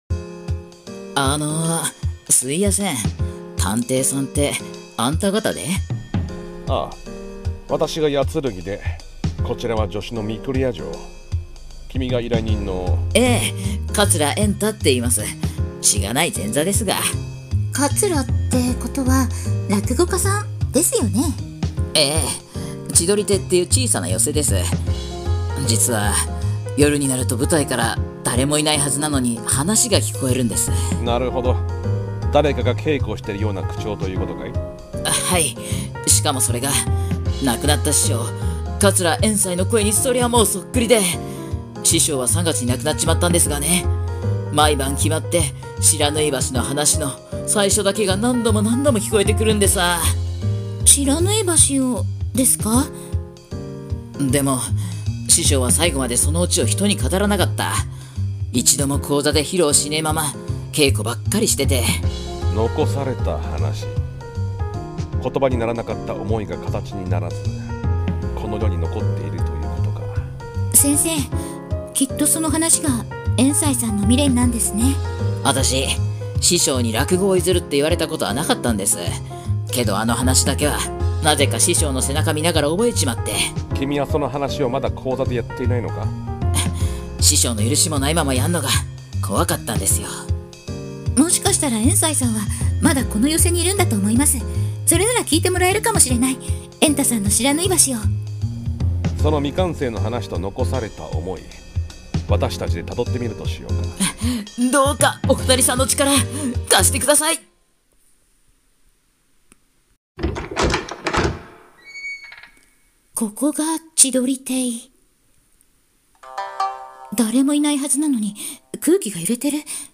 【三人声劇】しらぬい噺 －帝都千夜探偵奇譚シリーズ 第二話－